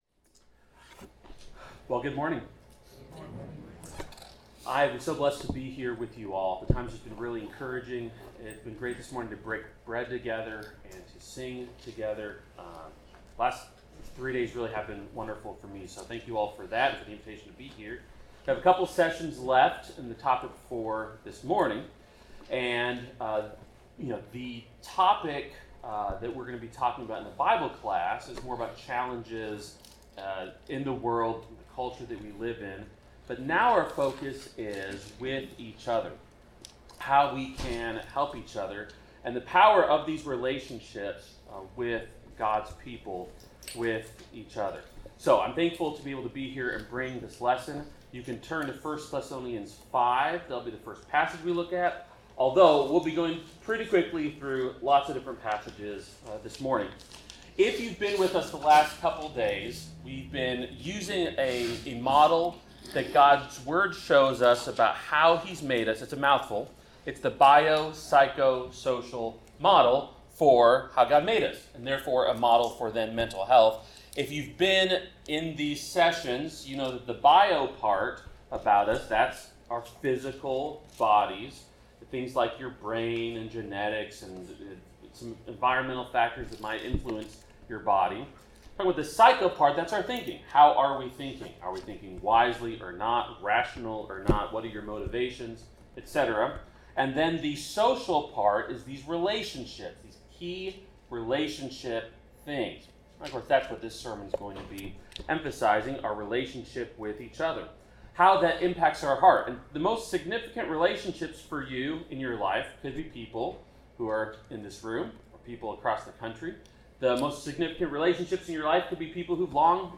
Series: GROW Conference 2025 Service Type: Sermon